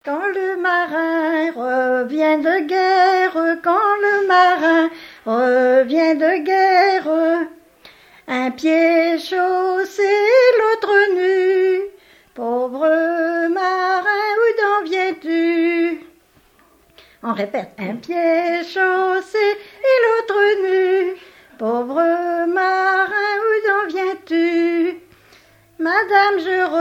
Genre strophique
Témoignages sur le mariage et chansons traditionnelles